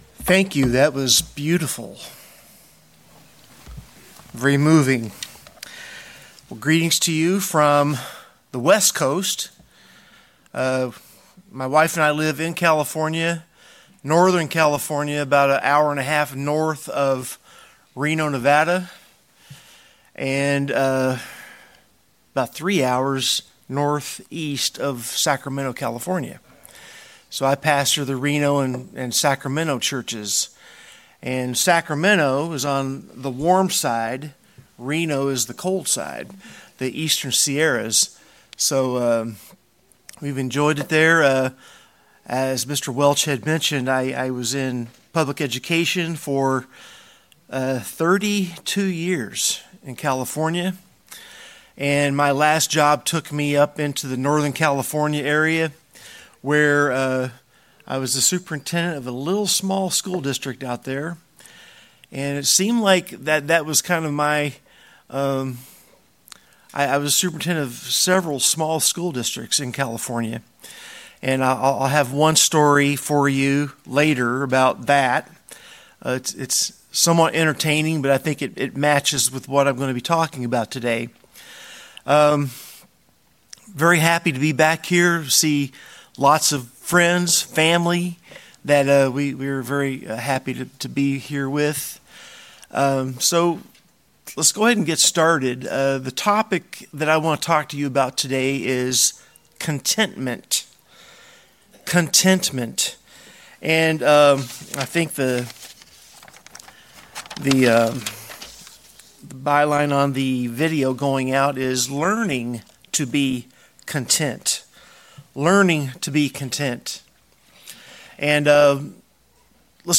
This sermon emphasizes the importance of trusting God and doing good, as taught in Psalm 37. It encourages us not to worry or fret, but to be thankful, help, and encourage others.